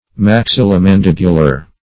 \Max*il`lo-man*dib"u*lar\
maxillomandibular.mp3